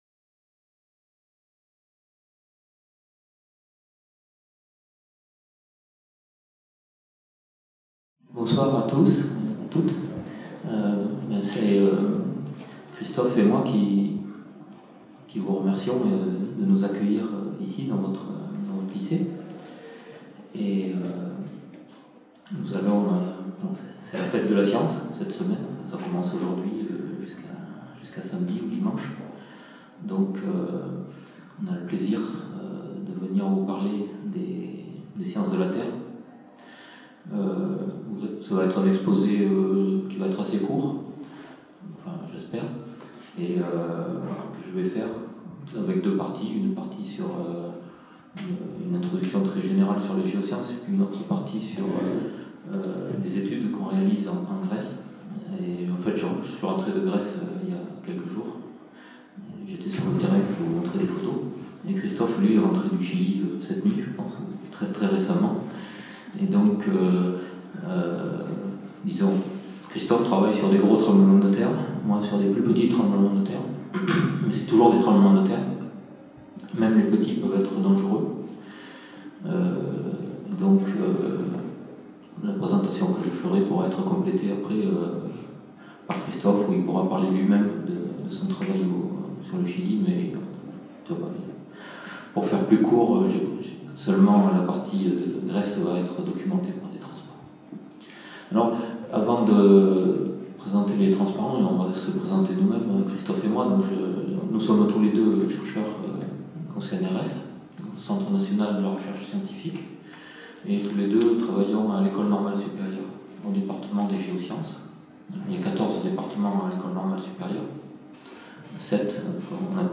A l'occasion de la Fête de la Science 2013, deux enseignants chercheurs, directeurs de recherche au CNRS, forts d'une longue expérience de terrain et de laboratoire, animent une conférence sur les métiers des géosciences. Cette présentation, bien qu'elle s'adresse notamment à des CPGE scientifiques et des élèves de terminale, s'avère, volontairement, d'un accès aisé et cible un large public.